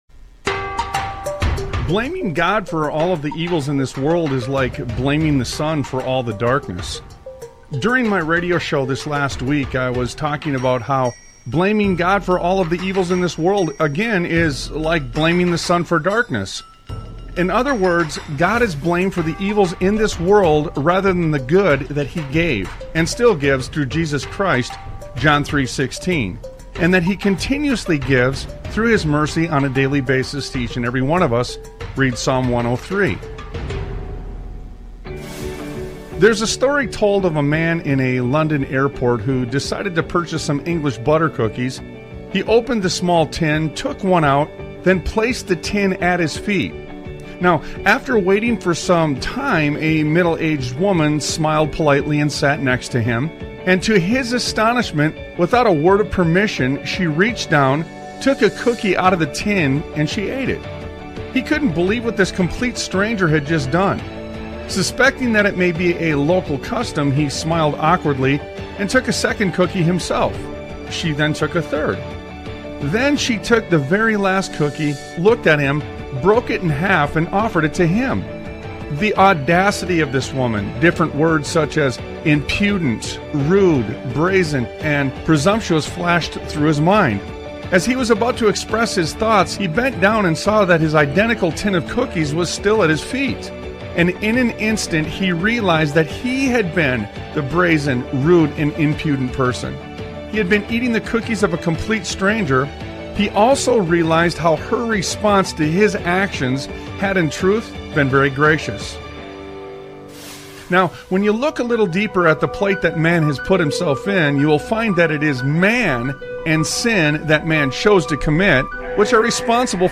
Talk Show Episode, Audio Podcast, Sons of Liberty Radio and Finish What I Started on , show guests , about Finish What I Started, categorized as Education,History,Military,News,Politics & Government,Religion,Christianity,Society and Culture,Theory & Conspiracy